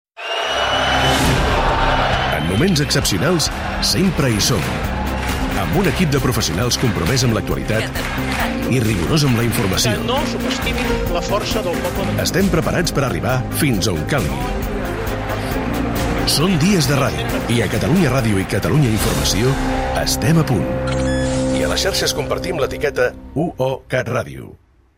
Promoció de la programació informativa especial amb motiu del referèndum d'autodeterminació unilateral convocat per la Generalitat de Catalunya el dia 1 d'octubre (sense esmentar-lo)
FM